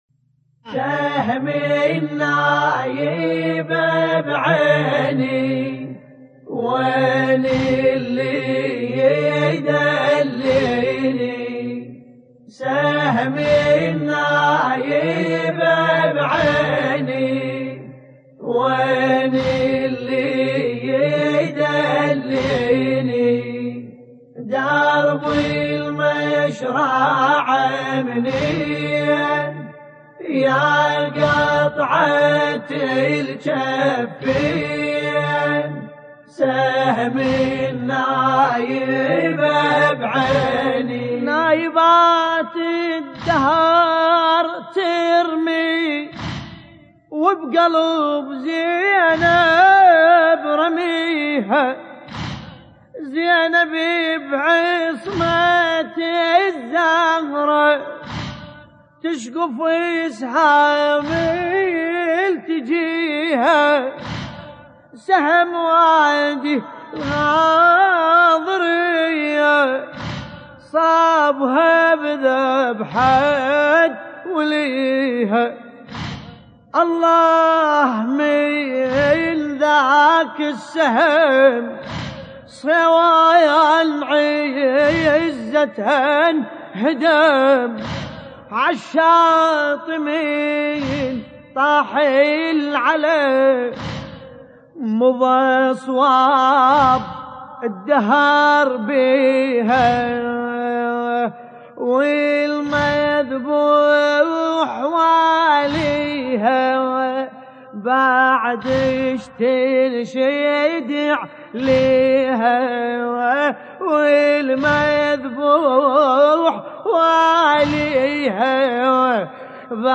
مع قدماء الرواديد